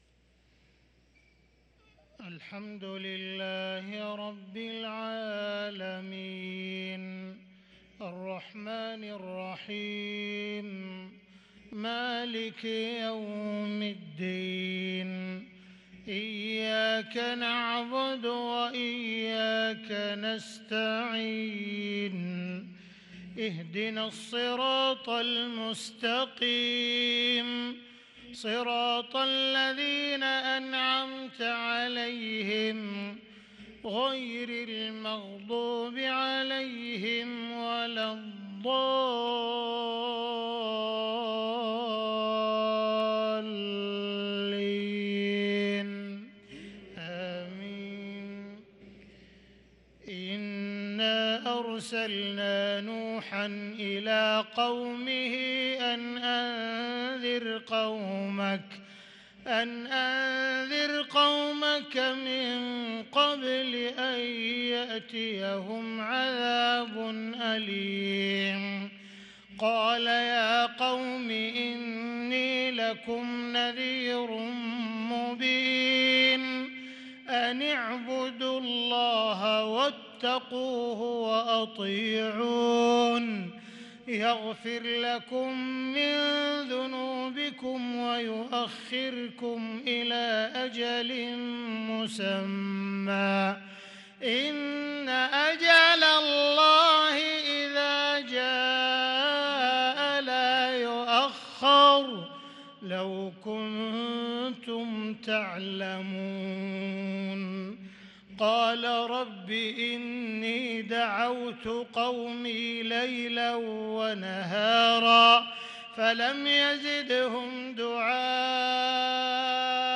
صلاة الفجر للقارئ عبدالرحمن السديس 23 ربيع الآخر 1444 هـ
تِلَاوَات الْحَرَمَيْن .